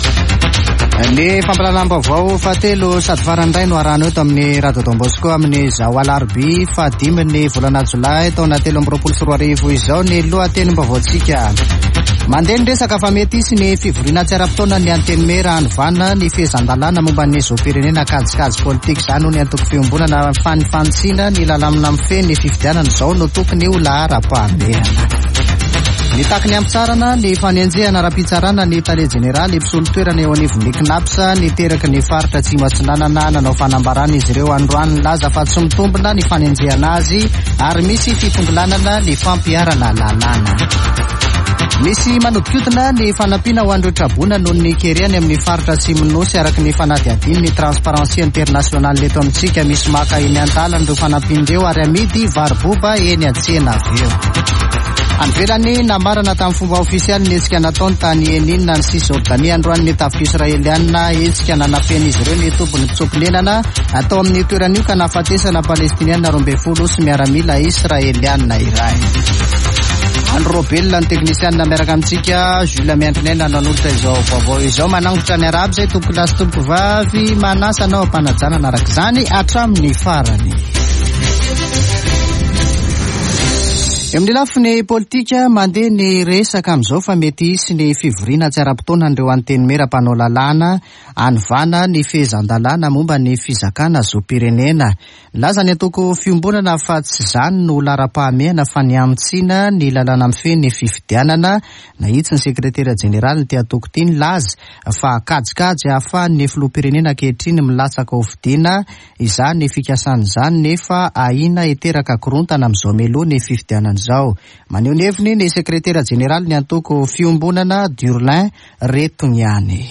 [Vaovao hariva] Alarobia 5 jolay 2023